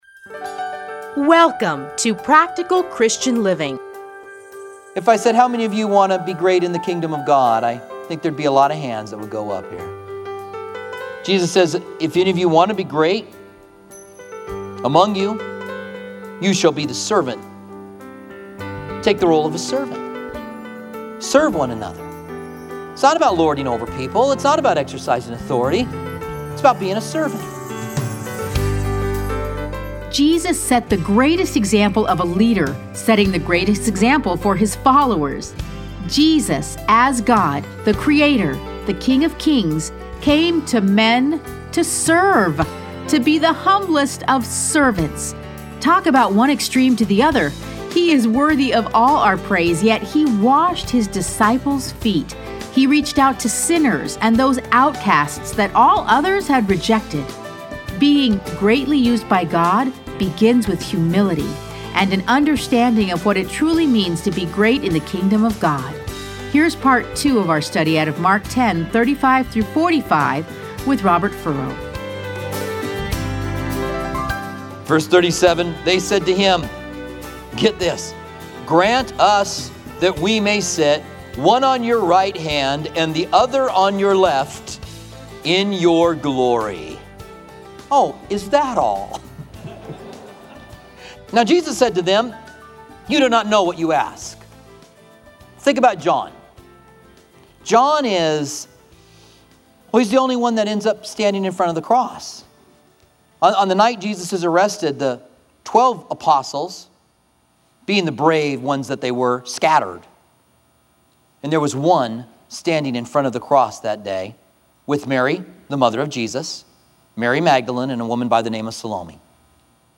Listen to a teaching from Mark 10:35-45.